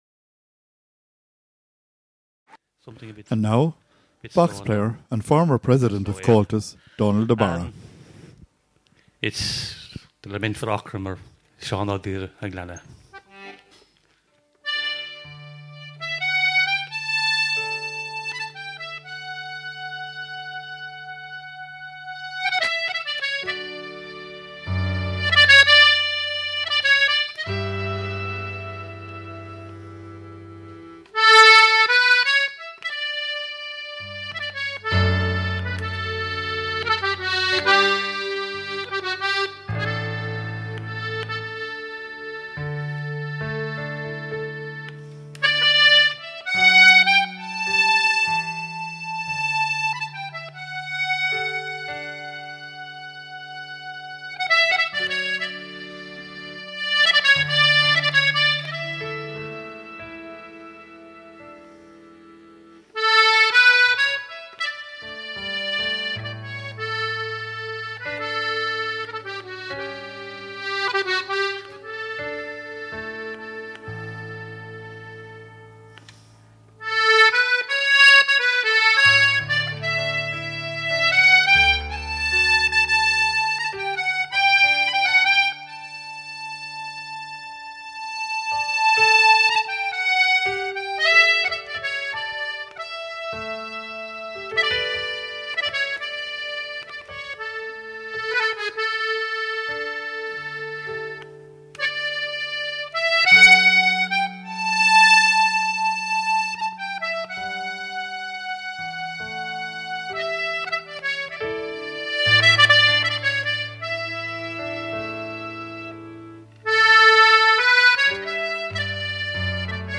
Slow Air
Button Accordion
Piano accompaniment
This music was recorded at the special concert of West Limerick music organized by the Munster council of Comhaltas at the Munster Fleadh in Newcastlewest, Co. Limerick in July 2007.
Fleadh, Concert, Slow Air, Button Accordion, Piano, ComhaltasLive